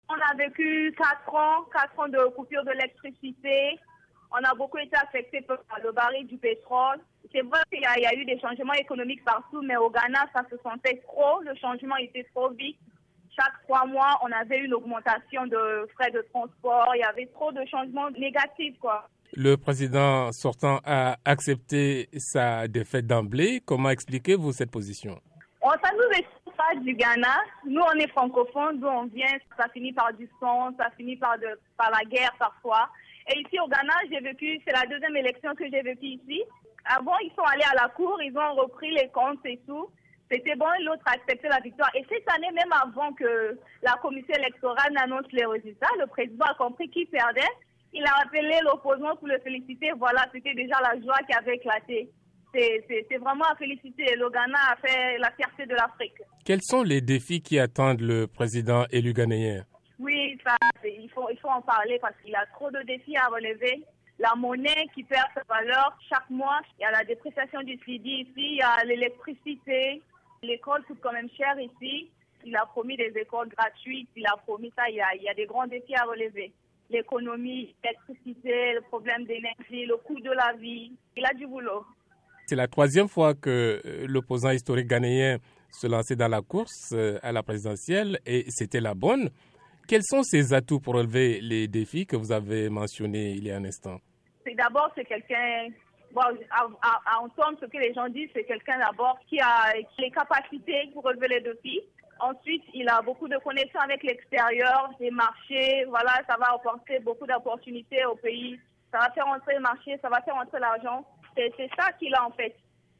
Un entretien